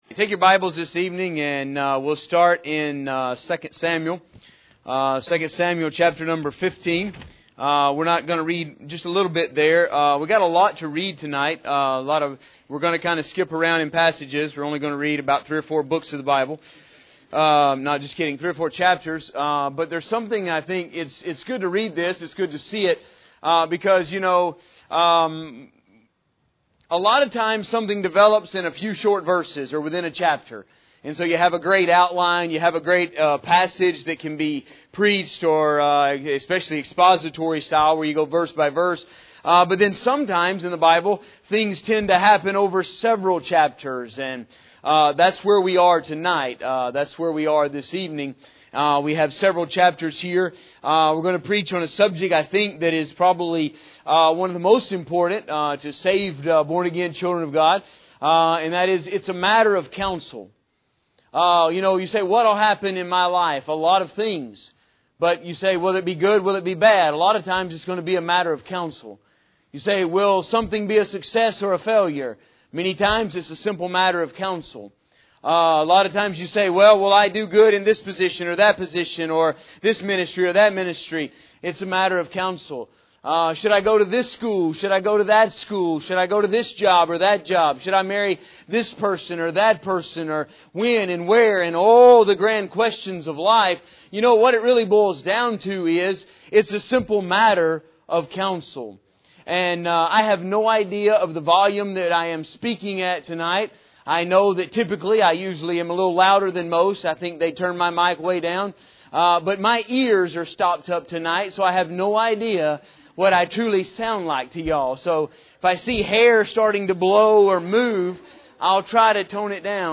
In this sermon we will deal with the subject of counsel and why it is so necessary in the lives of saved people. The passage we will use is lengthy but it shows us the story of how Absalom takes over the kingdom and is destroyed in the process.